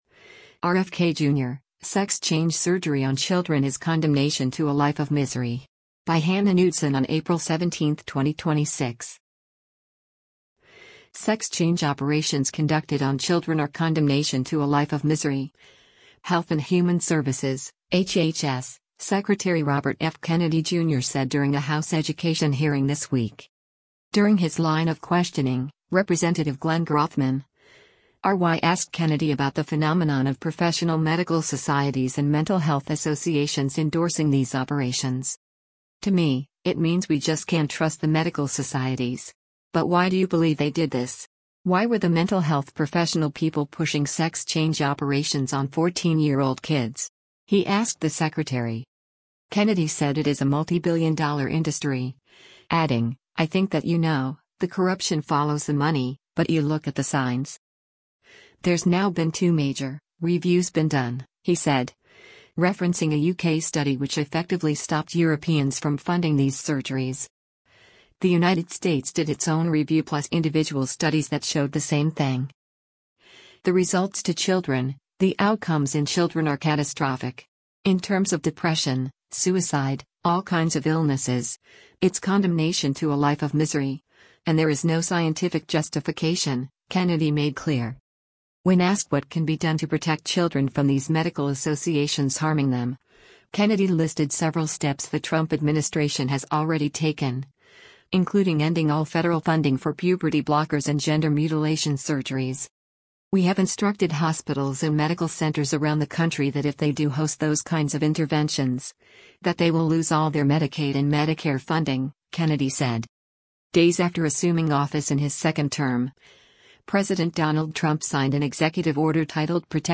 U.S. Health and Human Services Secretary Robert F. Kennedy Jr. testifies during a hearing
“Sex change” operations conducted on children are “condemnation to a life of misery,” Health and Human Services (HHS) Secretary Robert F. Kennedy Jr. said during a House education hearing this week.
During his line of questioning, Rep. Glenn Grothman (R-WI) asked Kennedy about the phenomenon of professional medical societies and mental health associations endorsing these operations.